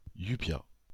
Llupia (French pronunciation: [jypja]